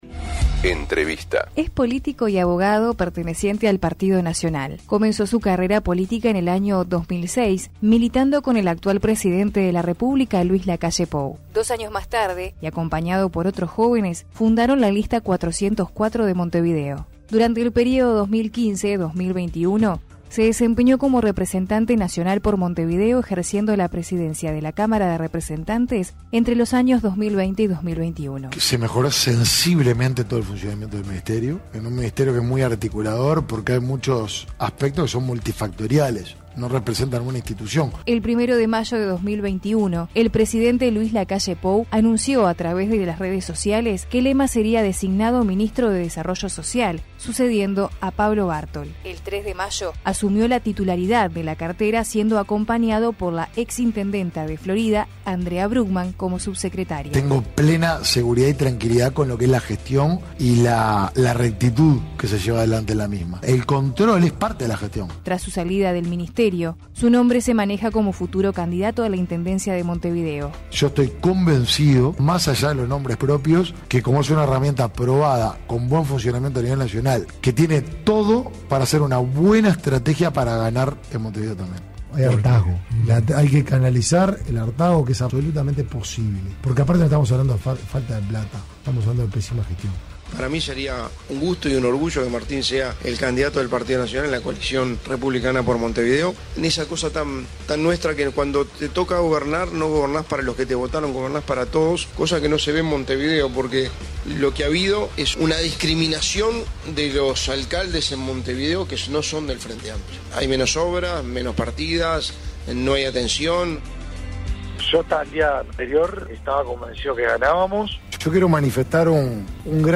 Entrevista con Martín Lema